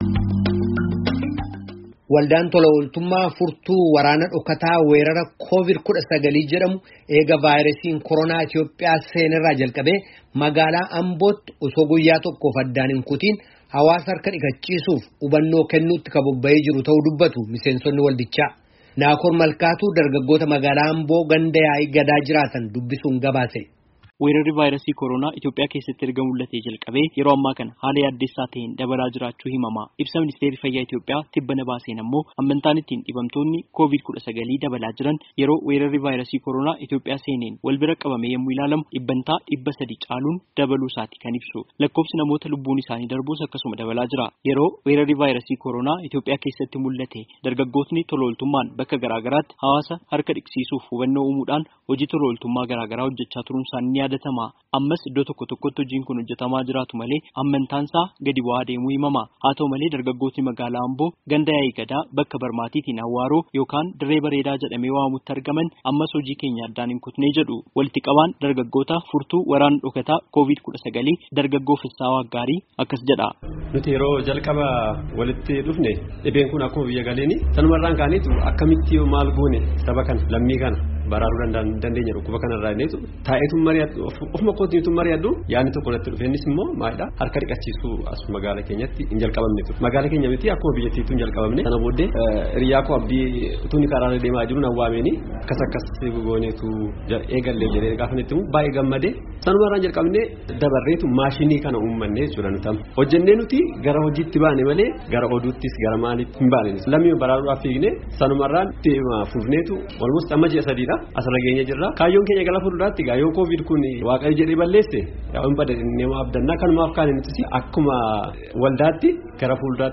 Dargaggoota magaalaa Amboo ganda Yaa’ii Gadaa jiraatan dubbisuun gabaase.